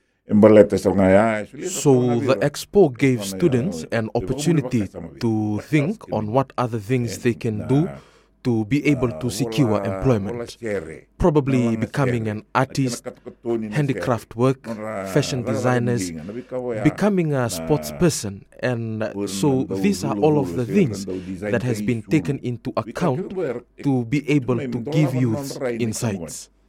Speaking during Radio Fiji One’s Na Noda Paraiminisita program, Rabuka highlights the challenges faced by graduates who struggle to secure jobs aligned with their academic disciplines.